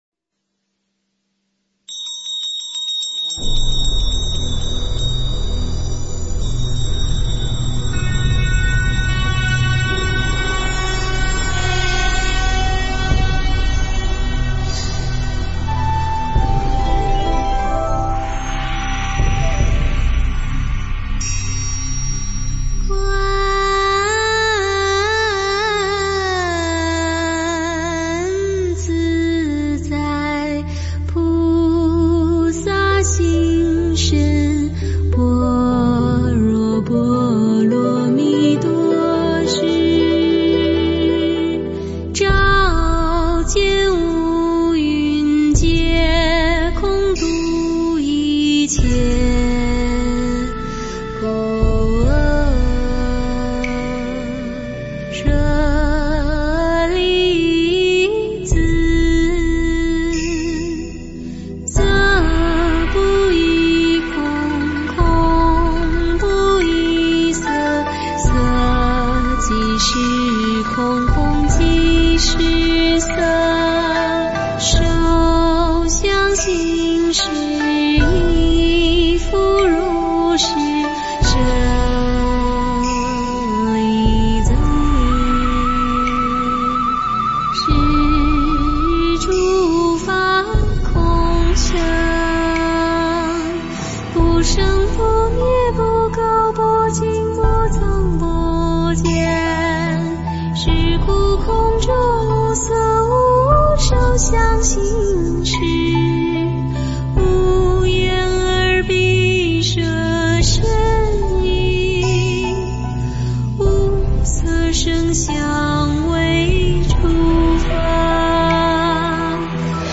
心经 诵经 心经--人间 点我： 标签: 佛音 诵经 佛教音乐 返回列表 上一篇： 般若波罗密多心经 下一篇： 心经-付嘱 相关文章 菩萨乘之六度所依--有声佛书 菩萨乘之六度所依--有声佛书...